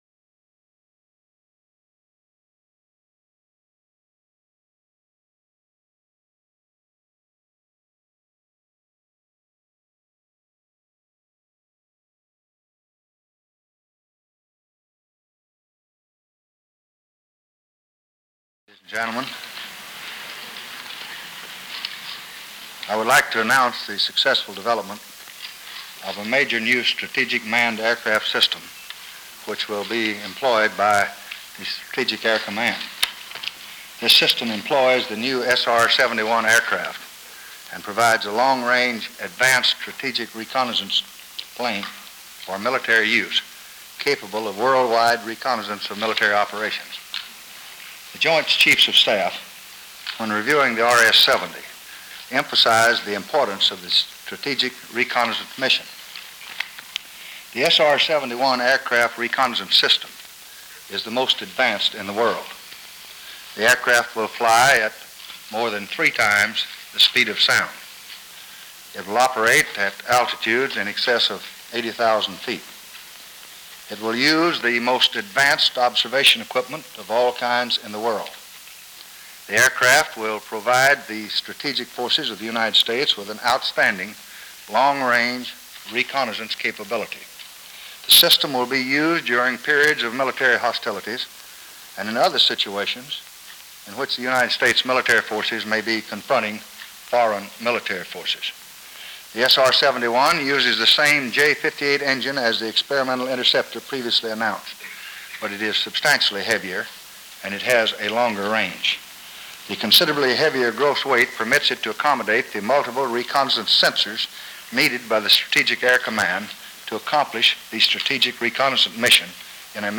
July 24, 1964: Press Conference at the State Department